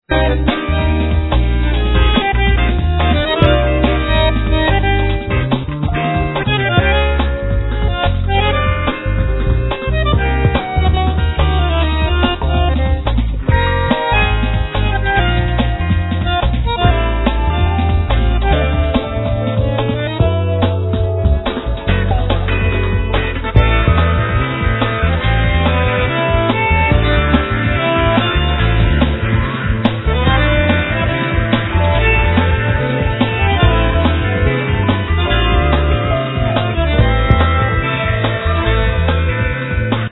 Bandoneon, Marimba, TR-808, Bongo, Drums
Bass
Synthesizer
Trumpet
Vocals
Berimbau, Pratinela, Cencerro